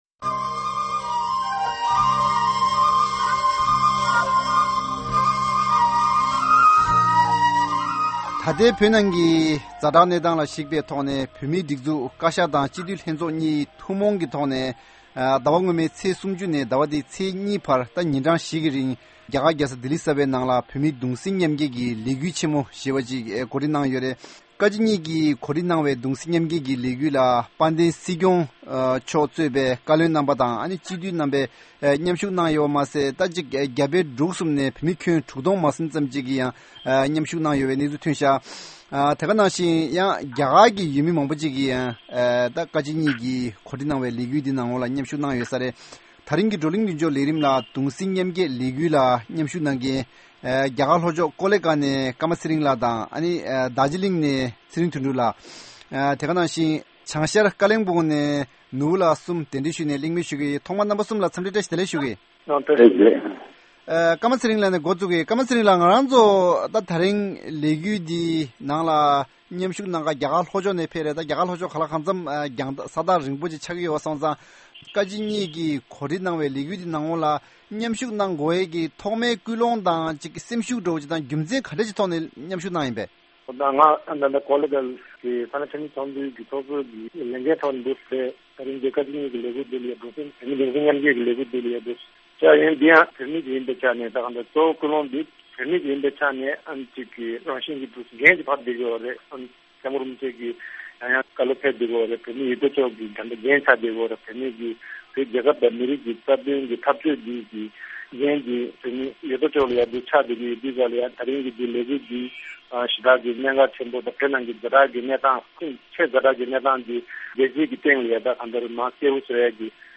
ད་ལྟའི་བོད་ནང་གི་གནས་སྟངས་ལ་གཞིགས་ཏེ་བཀའ་སྤྱི་གཉིས་ཐུན་མོང་གིས་ནས་རྒྱ་གར་གྱིས་རྒྱལ་ས་ལྡི་ལི་གསར་བའི་ནང་བོད་མི་དྲུག་སྟོང་ལྷག་འདུ་འཛོམས་ཐོག་ཉིན་བཞིའི་རིང་གདུང་སེམས་མཉམ་བསྐྱེད་ཀྱི་ལས་འགུལ་གཞི་རྒྱ་ཆེན་པོ་ཞིག་སྤེལ་ཡོད་པ་རེད། ད་རིང་གི་བགྲོ་གླིང་མདུན་ལྕོག་ལས་རིམ་ལ་གདུང་སེམས་མཉམ་བསྐྱེད་ལས་འགུལ་ལ་མཉམ་ཞུགས་བྱེད་མཁན་བོད་མི་གསུམ་དང་བགྲོ་གླིང་བྱེད་ཀྱི་ཡོད།